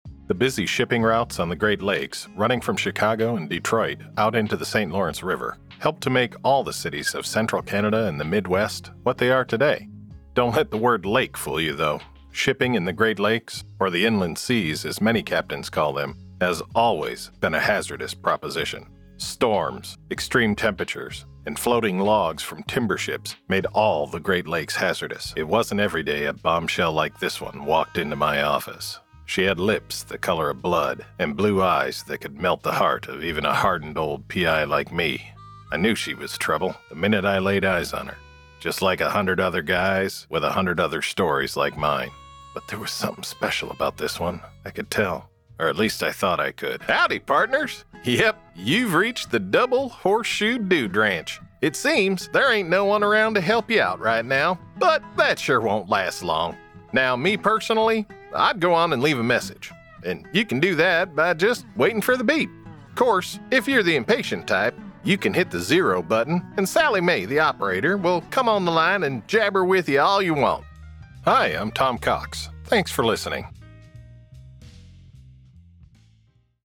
Narrative Demo
Middle Aged